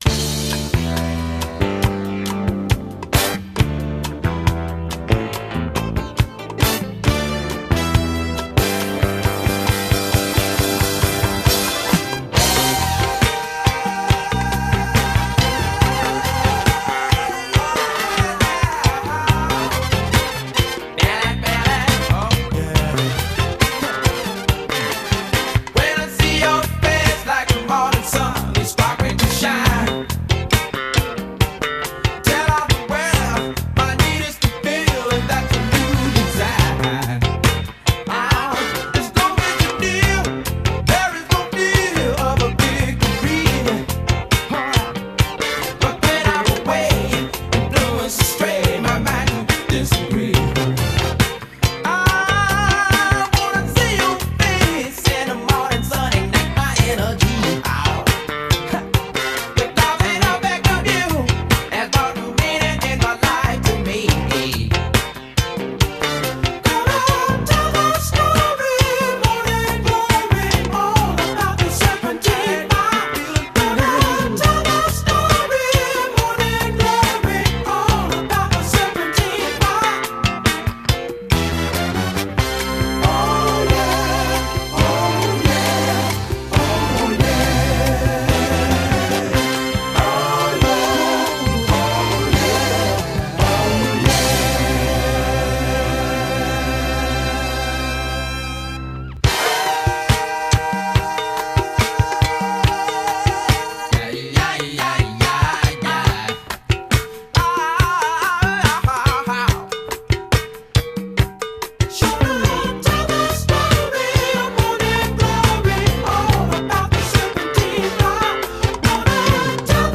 BPM115-143
Audio QualityMusic Cut